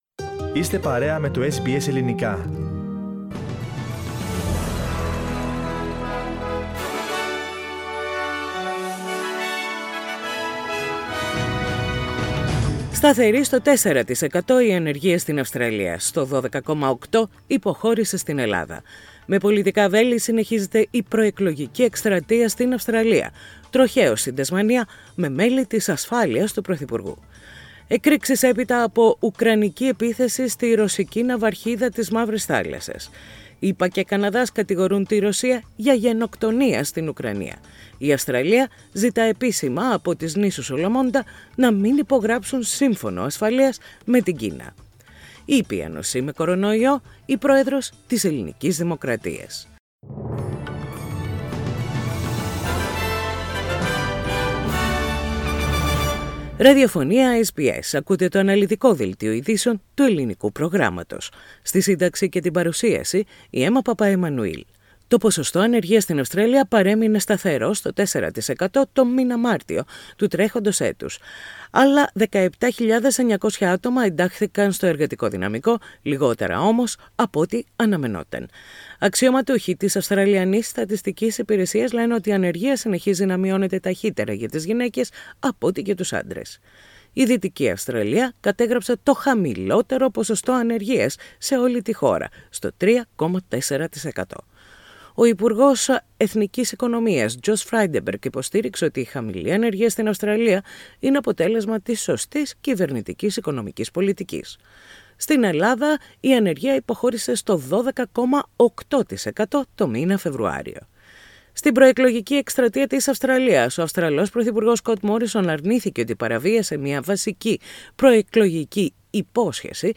Δελτίο Ειδήσεων - Πέμπτη 14.4.22
News in Greek. Source: SBS Radio